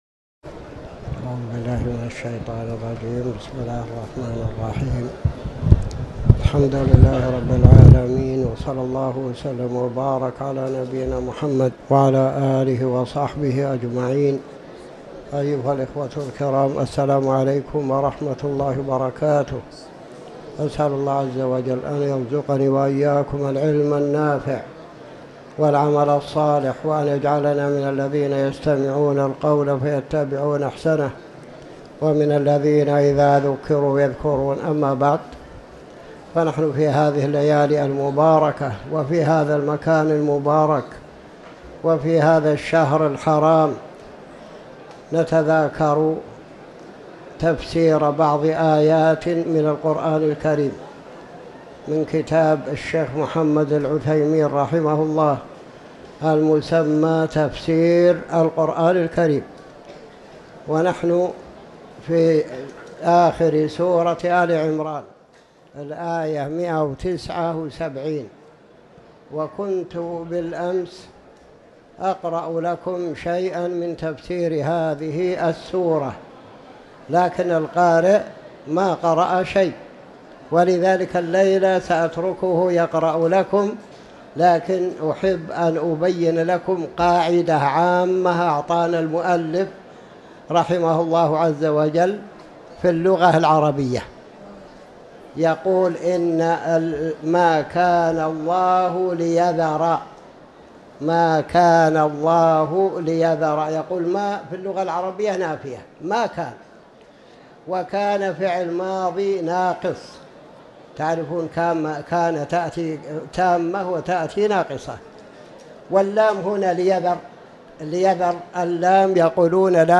تاريخ النشر ٣ رجب ١٤٤٠ هـ المكان: المسجد الحرام الشيخ